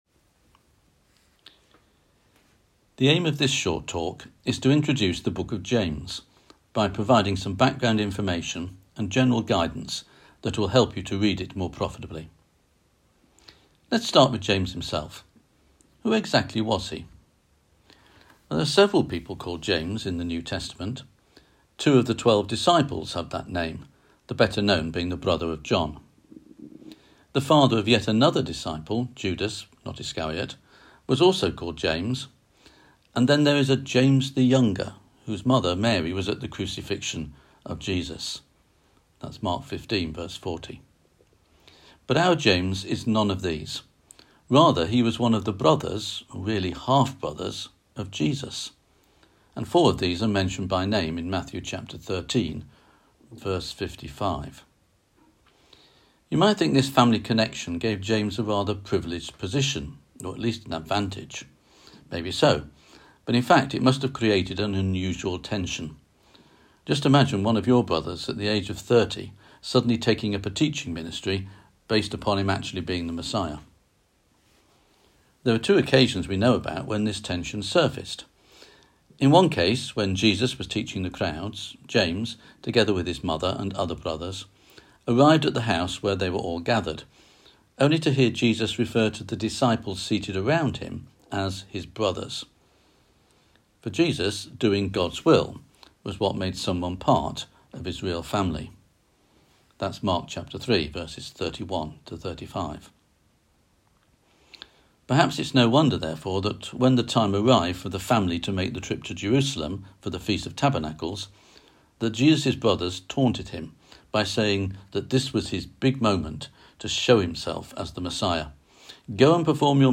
On September 29th at 7pm – 8:30pm on ZOOM SUBJECT